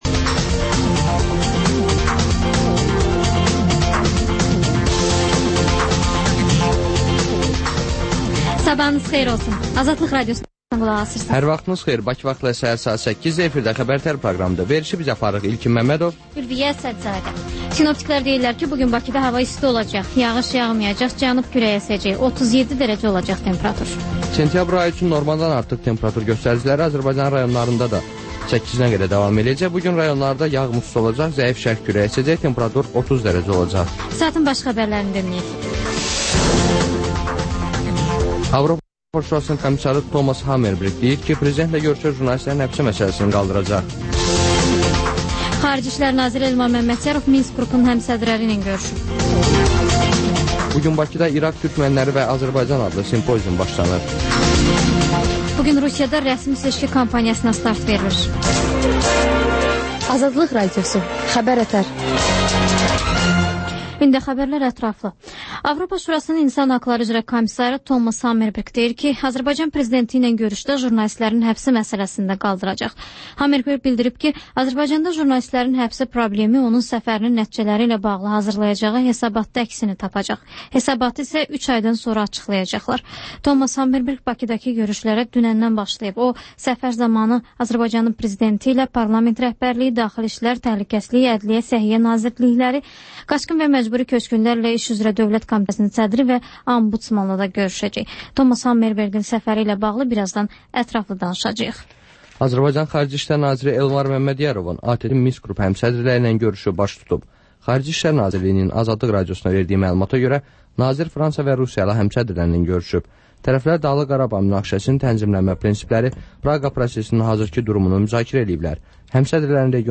Xəbər-ətər: xəbərlər, müsahibələr, sonda XÜSUSİ REPORTAJ rubrikası: Ölkənin ictimai-siyasi həyatına dair müxbir araşdırmaları